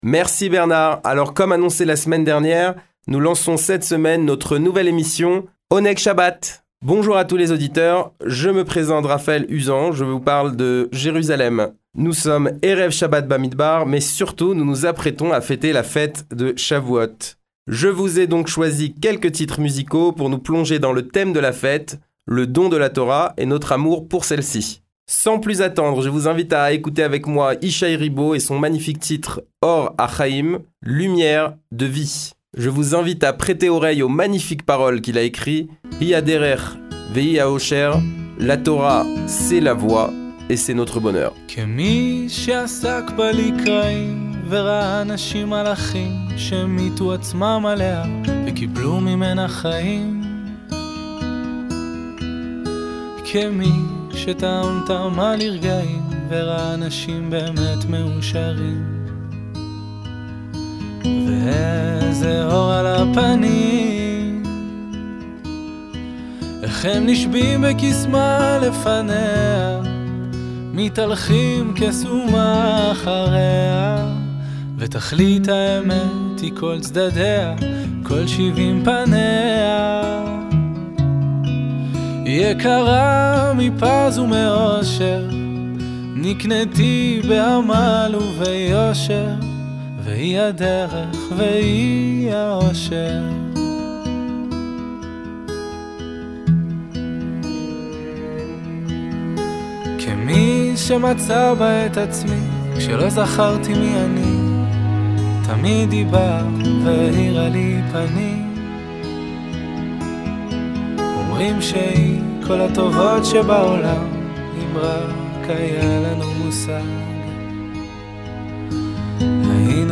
Le meilleur de la musique juive, tous les vendredis après-midi juste après Kabalat shabat, aux alentours de 17h20 !